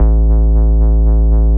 TI100BASS1-L.wav